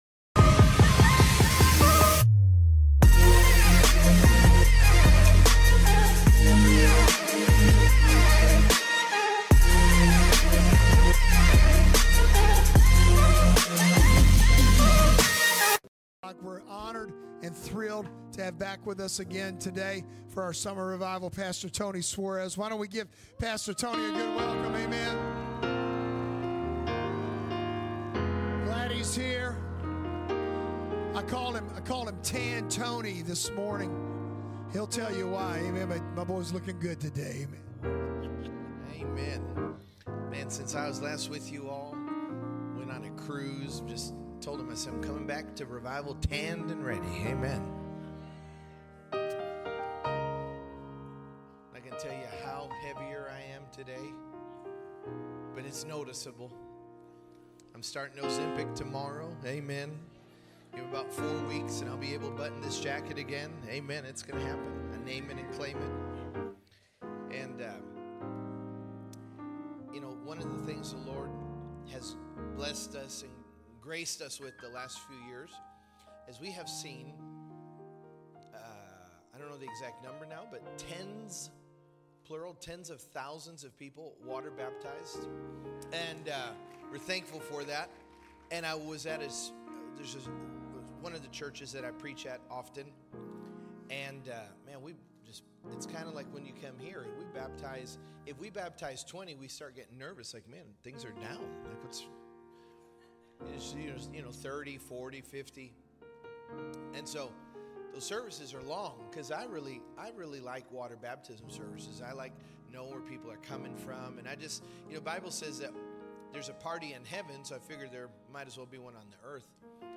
In this powerful message, we're reminded of the four keys to ruling and reigning in our spiritual lives, inspired by the life of King David. These keys are prayer, generosity, praise, and keeping the oil of the Holy Spirit.